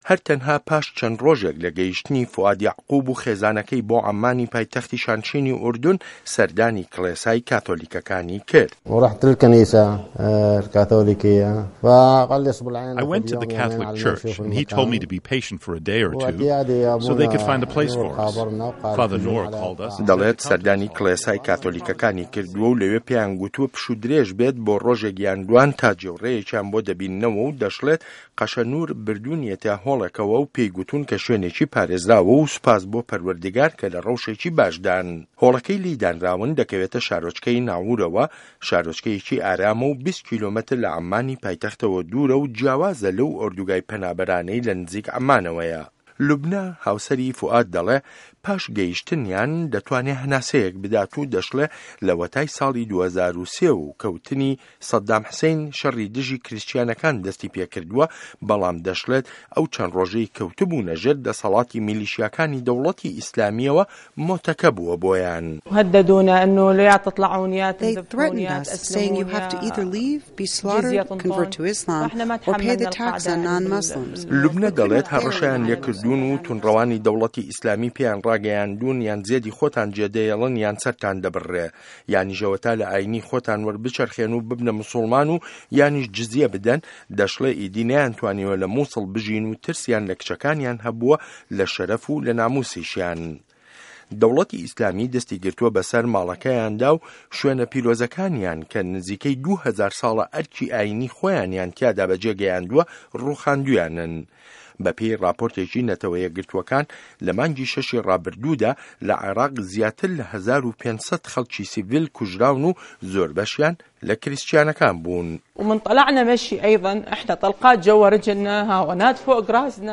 ڕاپۆرتی به‌سه‌رهاتی خێزانێکی کریسچیانی عێراق